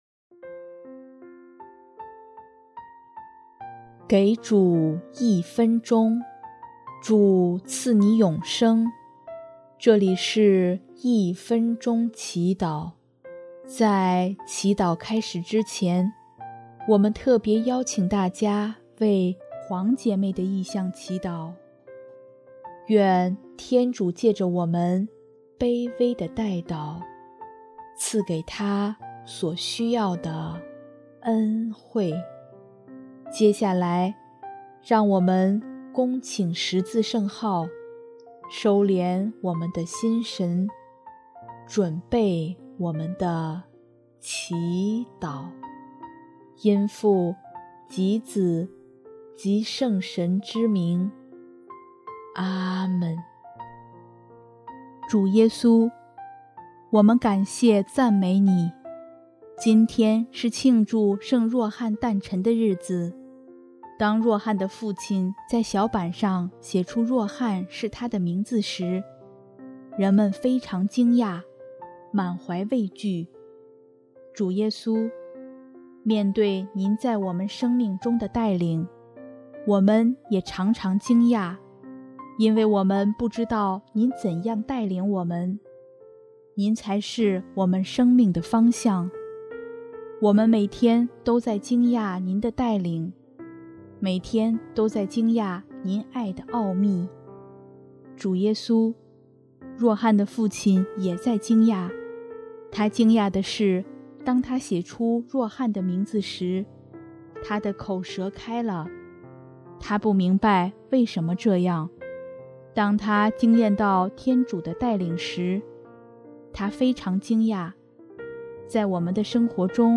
音乐：主日赞歌《前驱》